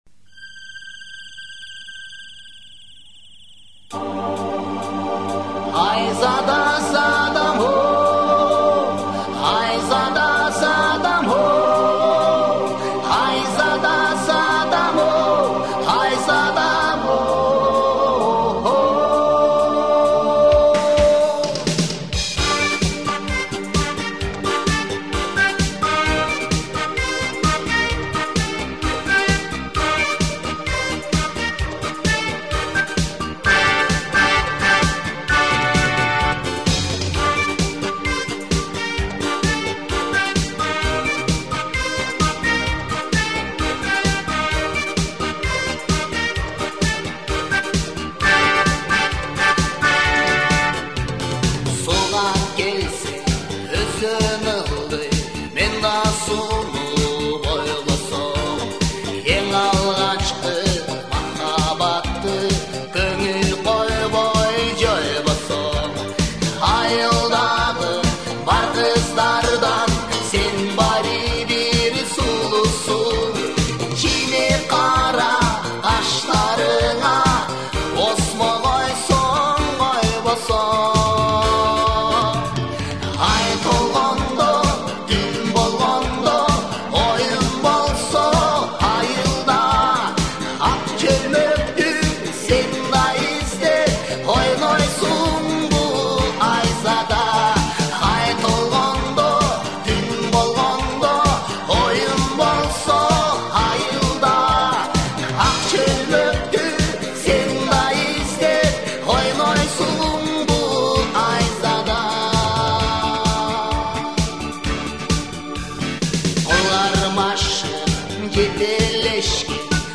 Трек размещён в разделе Киргизская музыка.